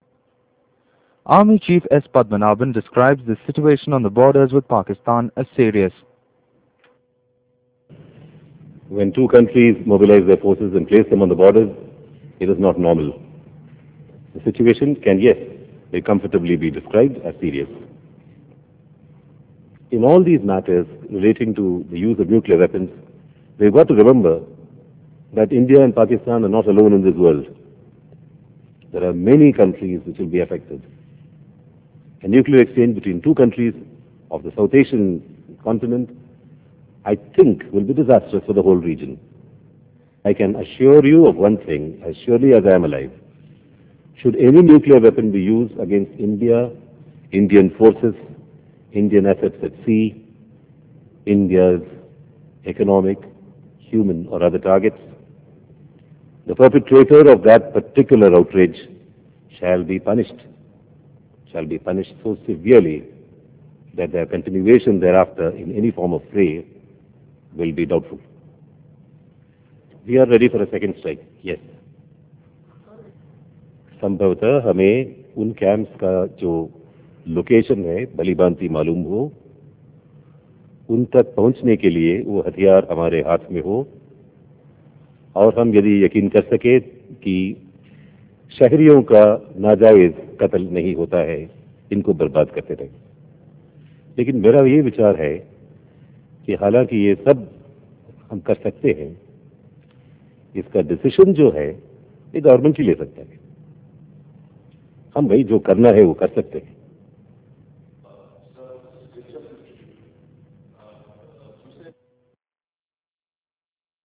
Gen. Padmanabhan addresses a Press conference in Delhi on Friday.